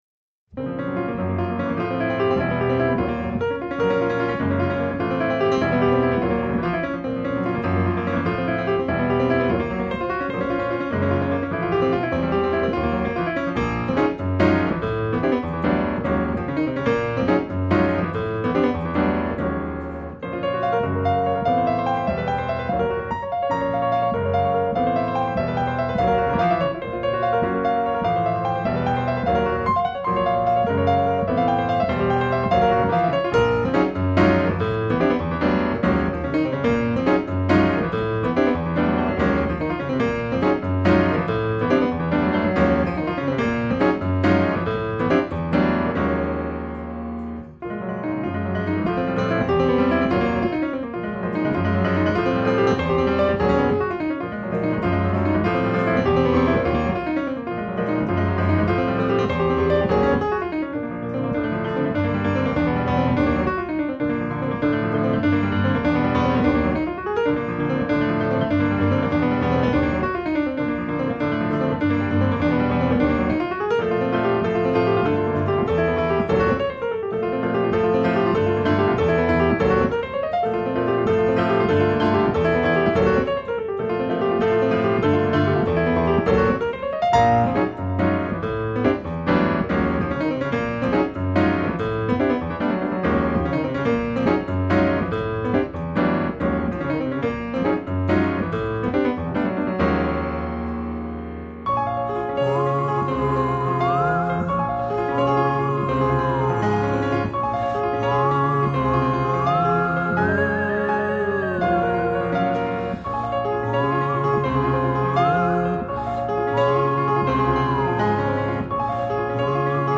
Armenian pianist
quintet